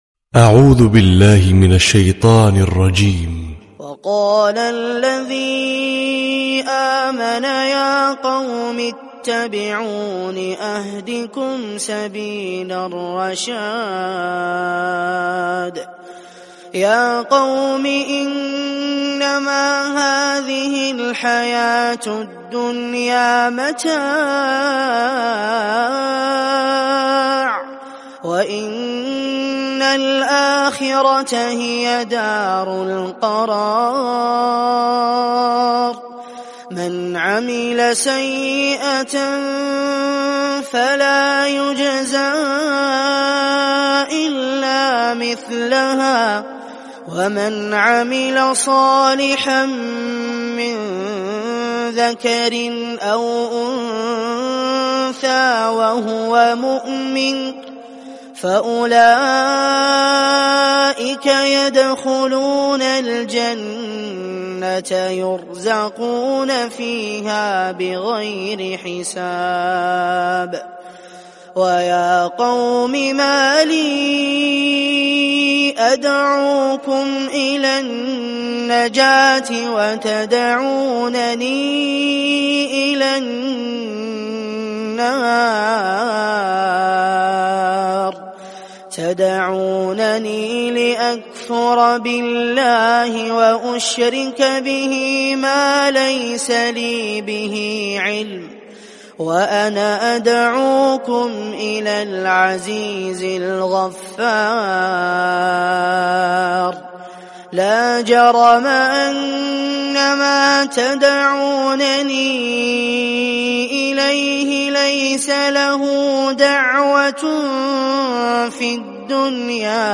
🌙🕌•تلاوة مسائية•🕌🌙